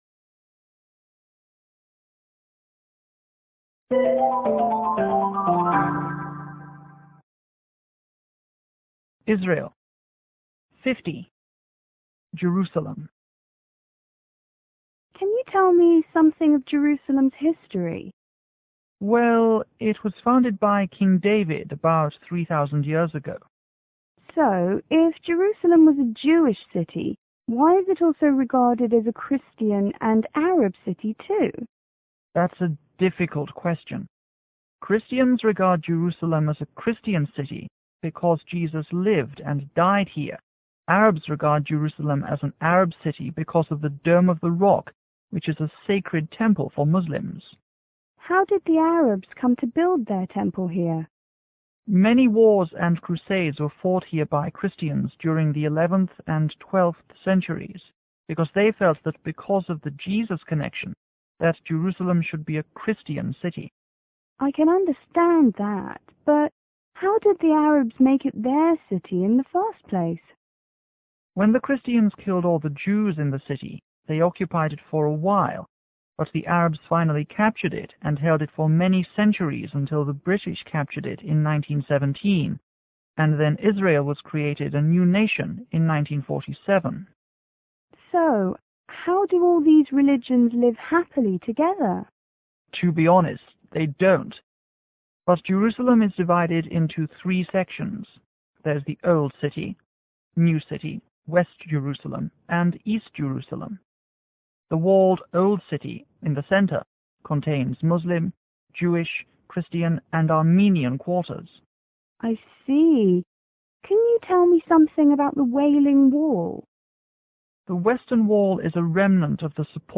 G：导游    T:游客